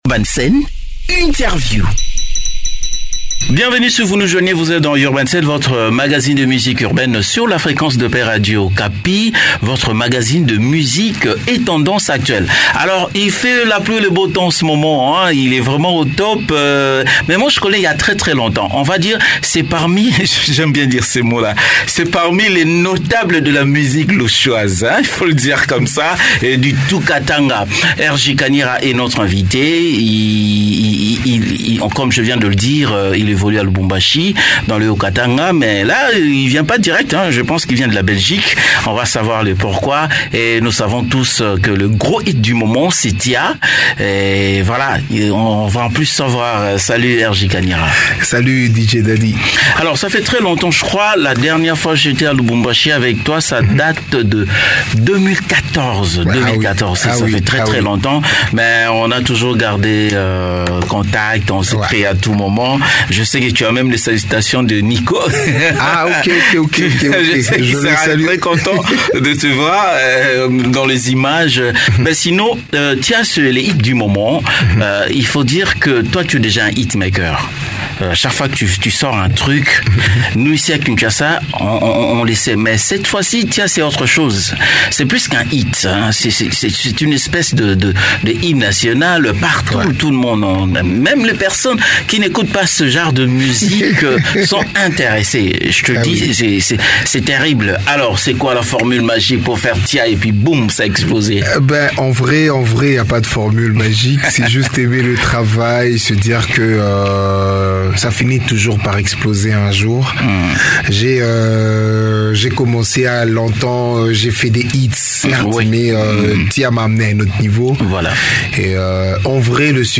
entretien exclusif